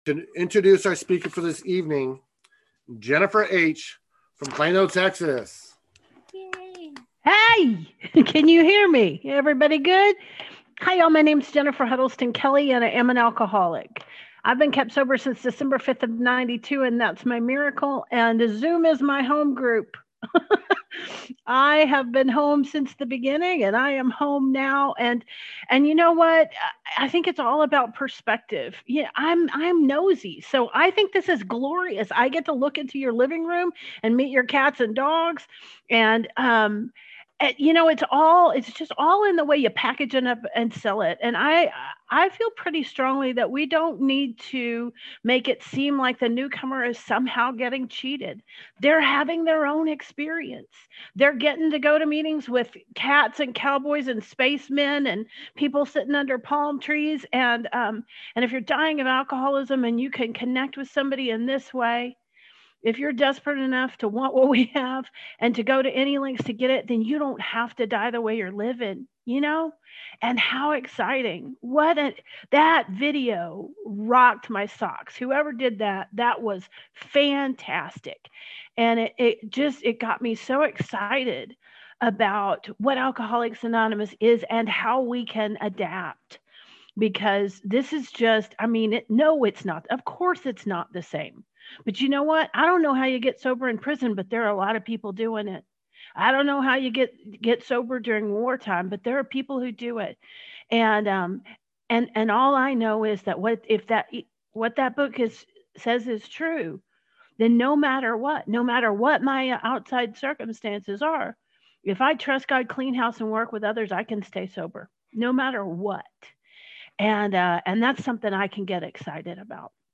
46th San Fernando Valley Alcoholics Anonymous UnConventional